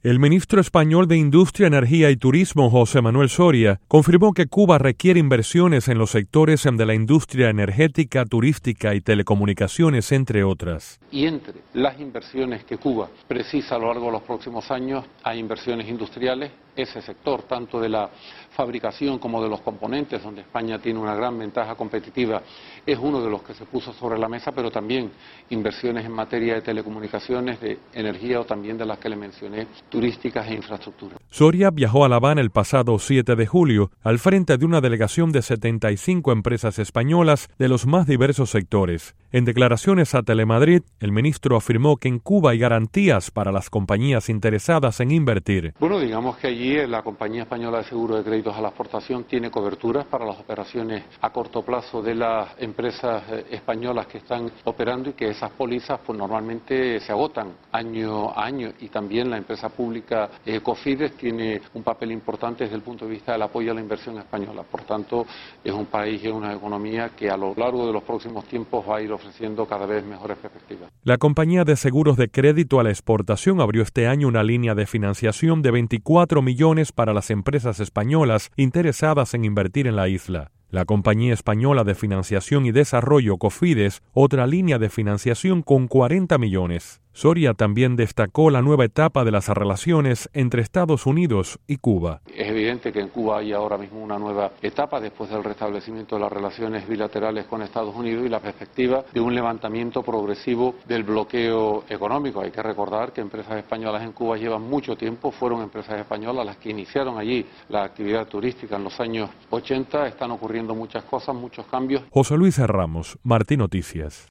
Declaraciones del Ministro español de Industria, Energía y Turismo, José Manuel Soria